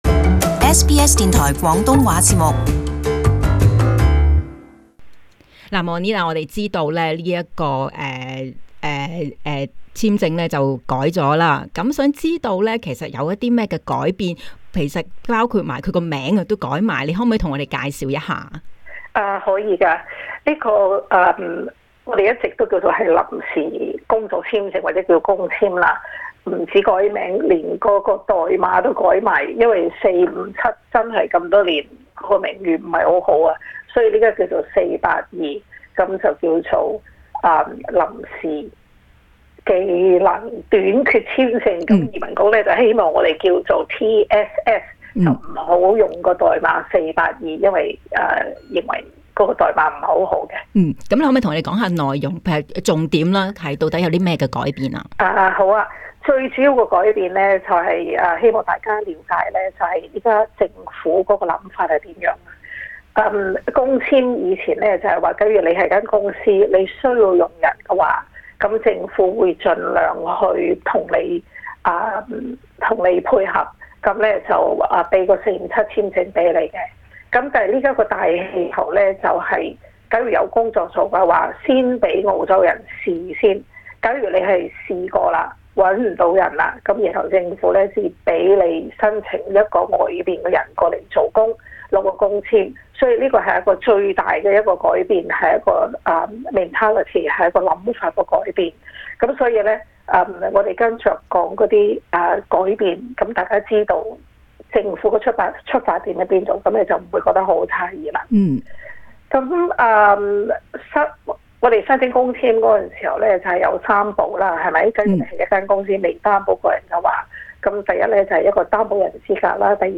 【社區專訪】482臨時技術短缺簽證